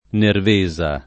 [ nerv %@ a ]